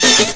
hitTTE68024stabhit-A.wav